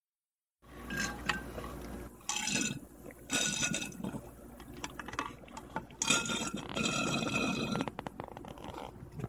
描述：最后一杯饮料被吸管吸入
Tag: 饮料 饮料 空饮料 稻草